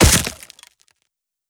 Hit_Wood 03.wav